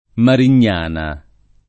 [ marin’n’ # na ]